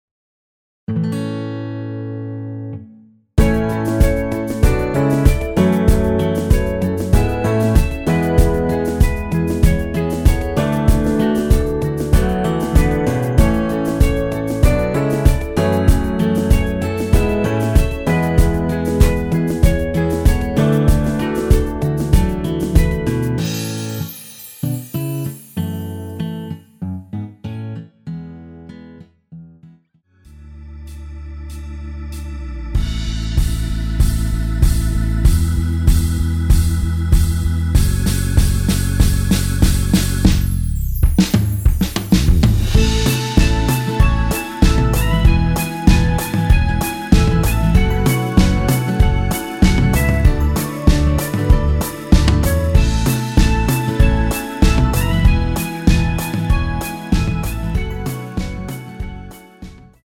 ◈ 곡명 옆 (-1)은 반음 내림, (+1)은 반음 올림 입니다.
키 A 가수